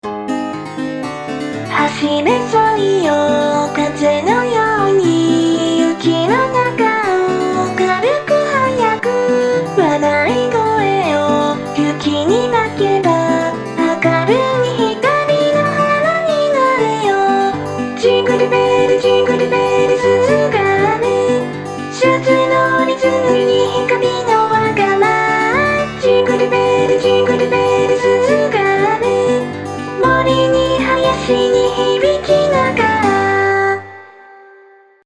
utau-jingle-bells.mp3